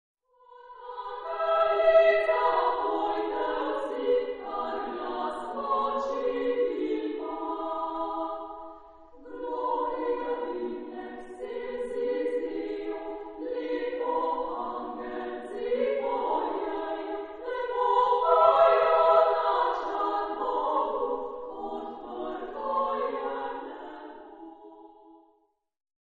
Epoque: 20th century
Genre-Style-Form: Christmas carol
Mood of the piece: simple ; moderate ; sincere
Type of Choir: SSA  (3 children OR women voices )
Tonality: E flat major